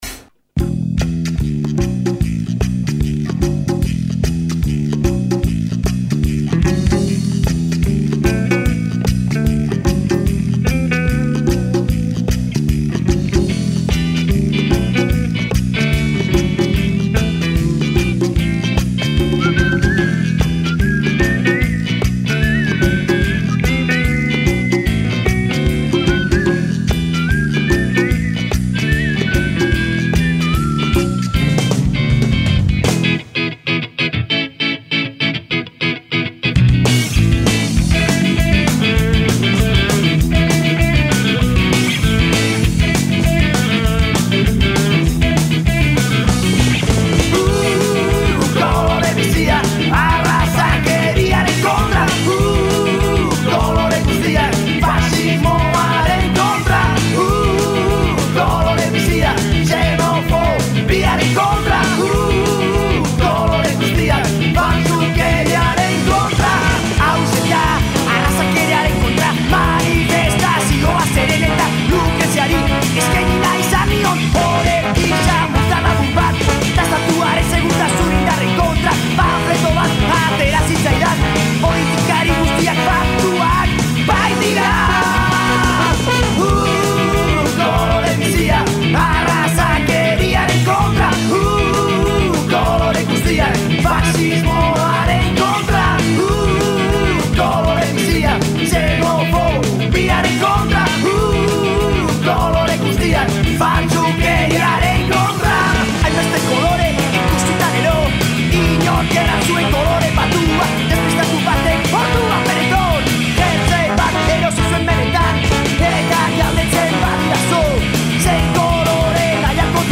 Un viaggio musicale dentro le culture latino americane. Dall’aeroporto di Madrid.